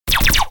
lasers.ogg